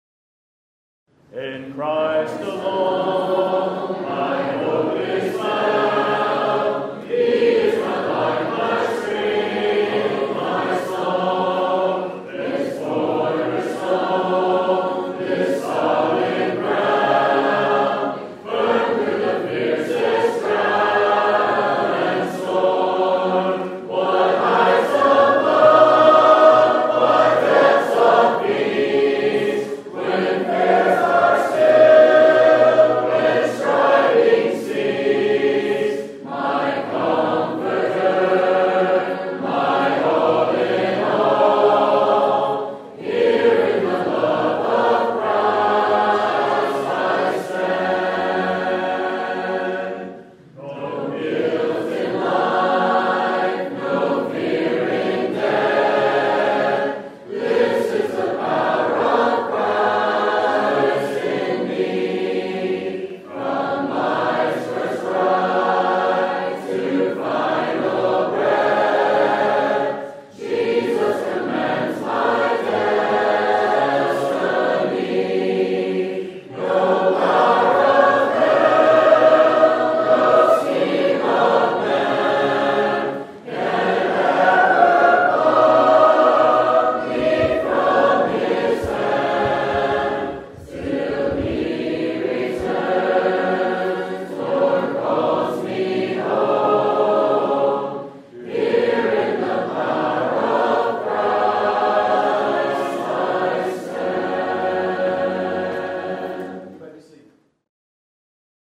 Congregational Singing, October 2020 (Individual Audio Recordings)
Please enjoy these recordings, formatted in individual tracks, from our October 2020 Anniversary Meeting.